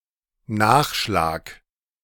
The word Nachschlag (German: [ˈnaːxʃlaːk]